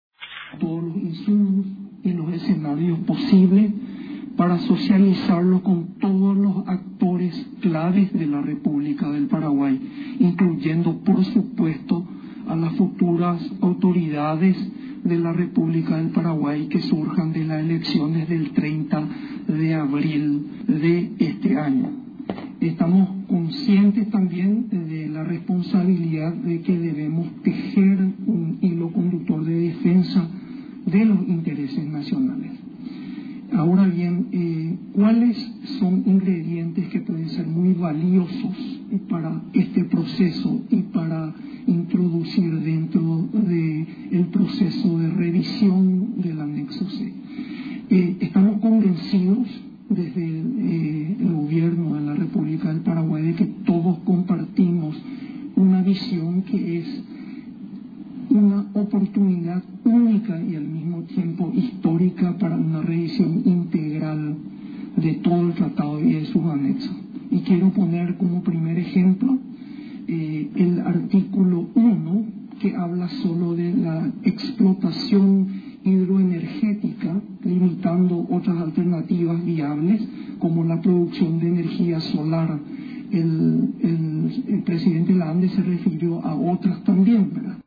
Asunción, Radio Nacional.-La revisión del Anexo C, es la oportunidad para una renegociación integral del Tratado de ITAIPU, destacó este miércoles el canciller nacional, Julio Arriola, ante los integrantes de la Comisión Permanente del Congreso Nacional.